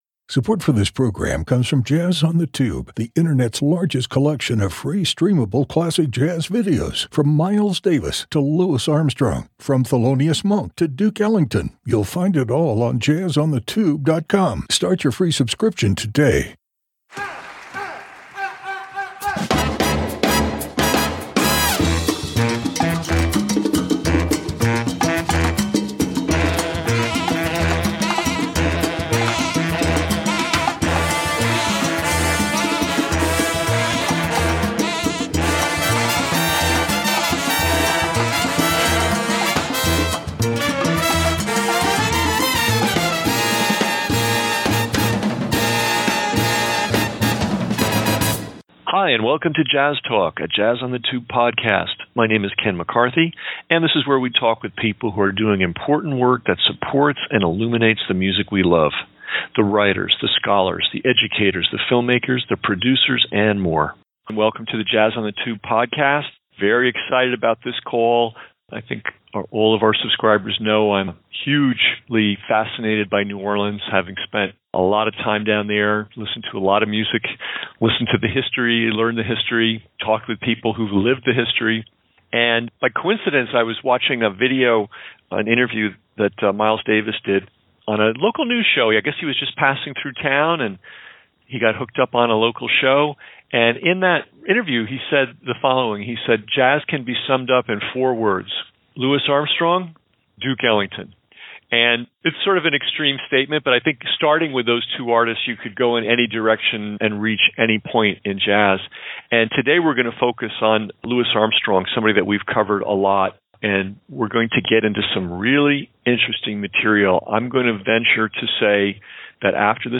Artist-Educators, Blog, Chroniclers, Jazz on the Tube Interview, Podcasts